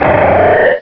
Fichier:Cri 0317 DP.ogg — Poképédia
Cri_0317_DP.ogg